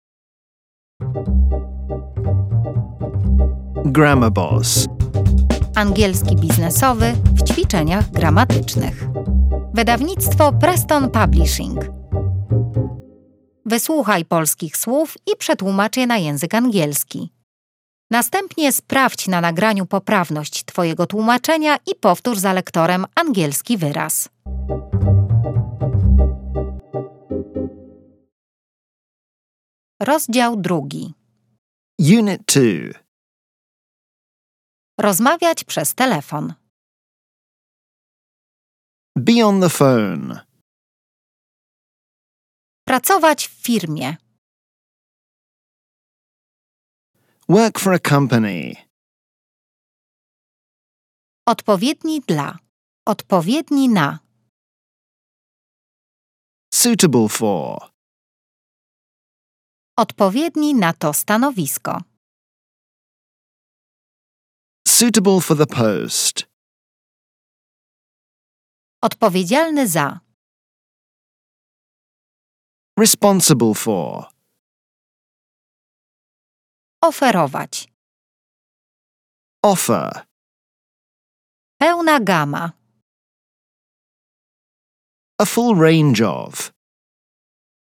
Dodatkowo otrzymasz dostęp do kursu audio – to kilka godzin nagrań z natywnym lektorem.
• kurs audio MP3 z native speakerem.